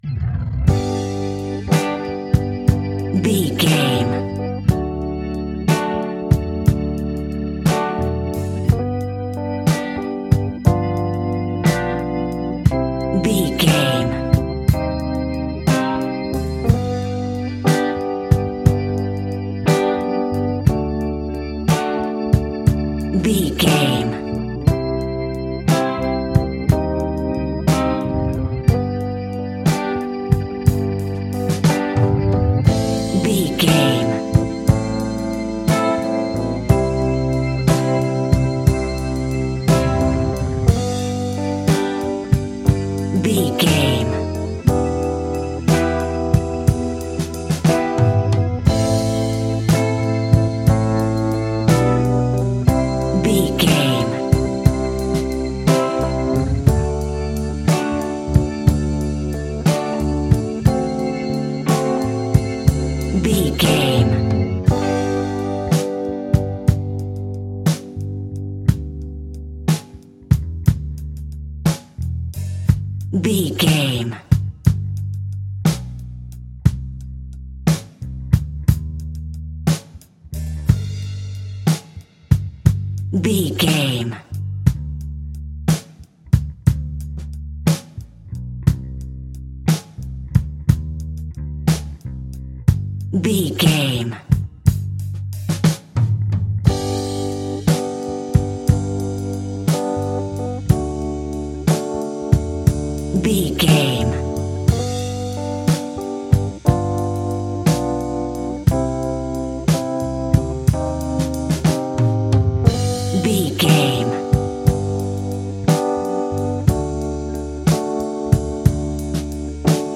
Aeolian/Minor
cool
funky
uplifting
bass guitar
electric guitar
organ
percussion
drums
saxophone
groovy